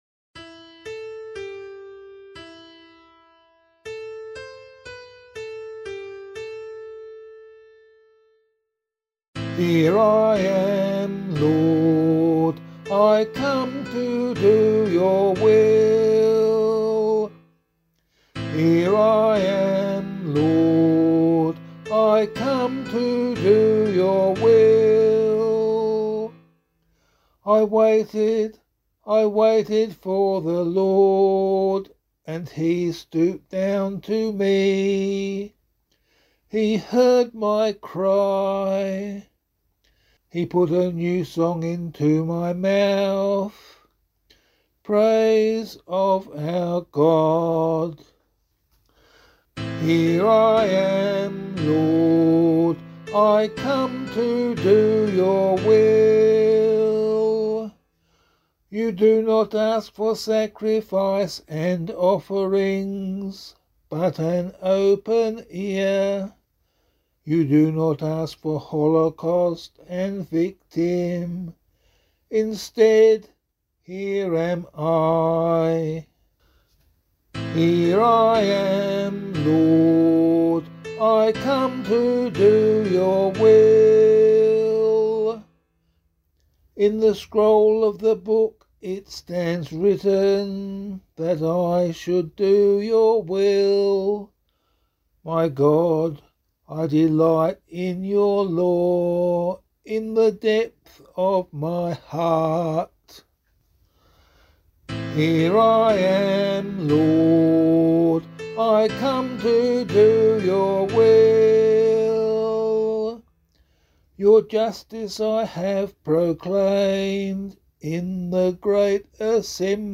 036 Ordinary Time 2 Psalm A [LiturgyShare 4 - Oz] - vocal.mp3